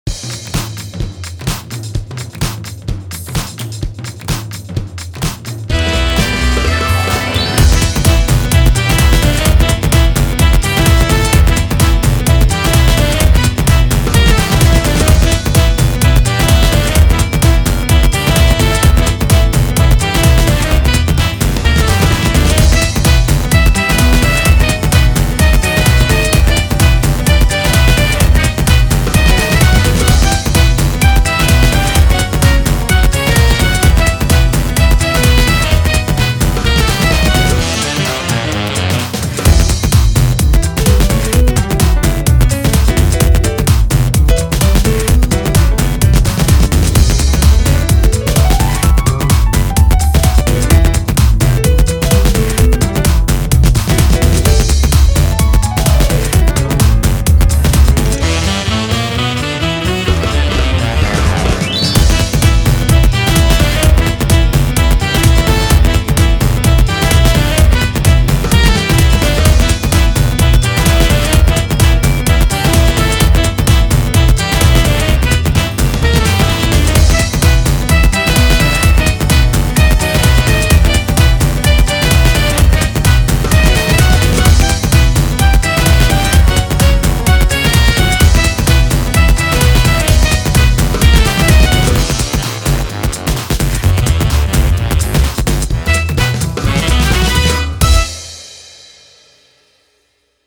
BPM128
Audio QualityPerfect (High Quality)
A fake jazz track